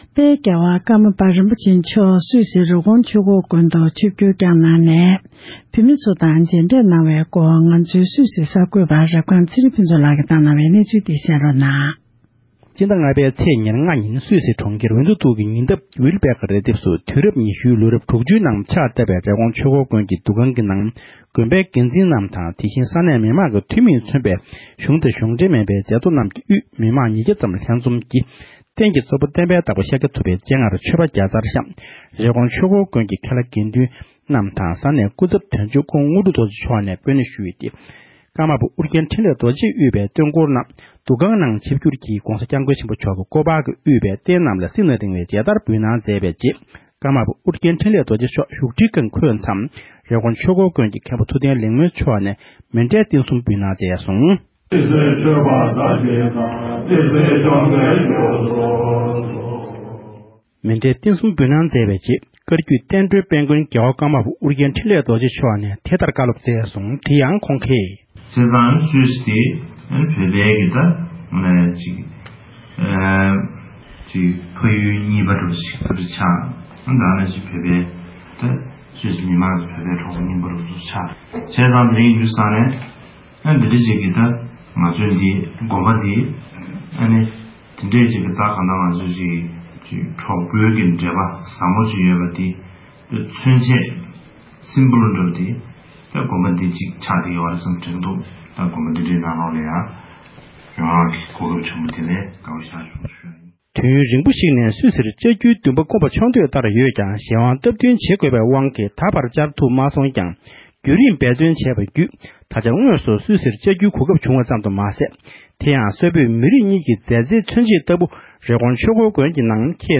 གནས་ཚུལ་ཕྱོགས་སྒྲིག་ཞུས་པར་གསན་རོགས༎